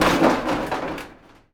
metal_sheet_impacts_08.wav